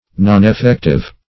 Meaning of noneffective. noneffective synonyms, pronunciation, spelling and more from Free Dictionary.